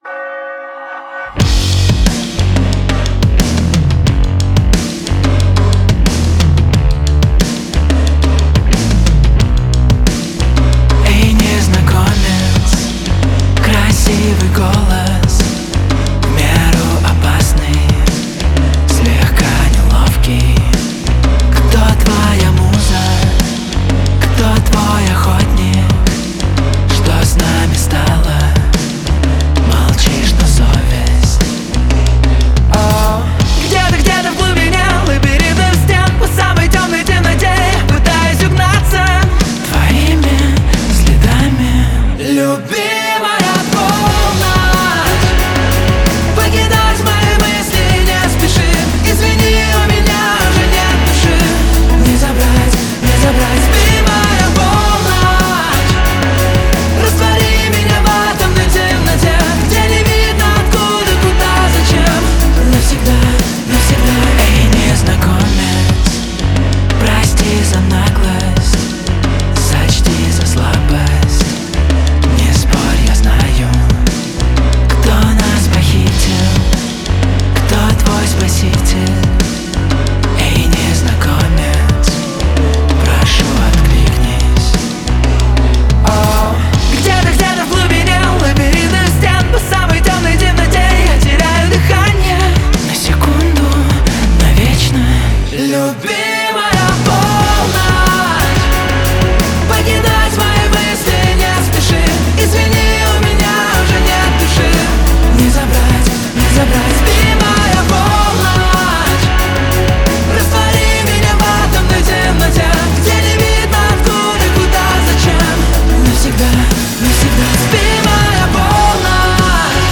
• Жанр: Альтернатива, Русская музыка